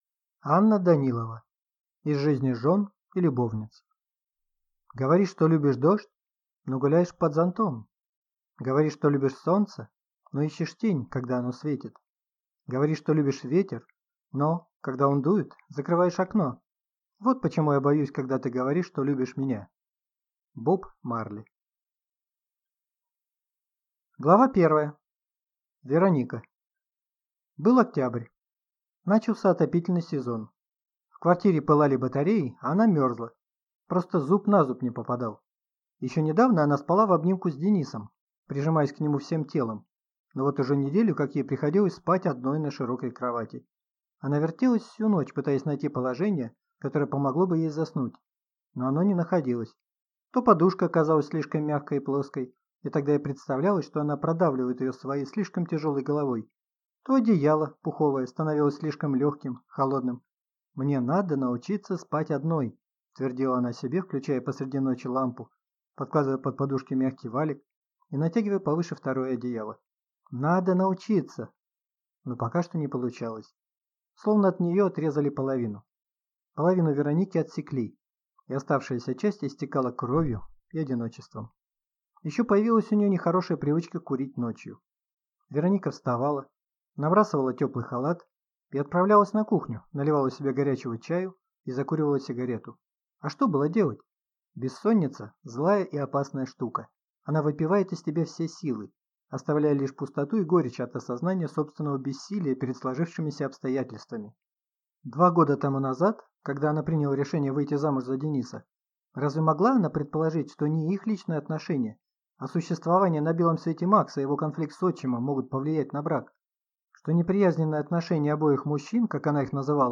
Аудиокнига Из жизни жен и любовниц | Библиотека аудиокниг